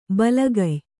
♪ balagay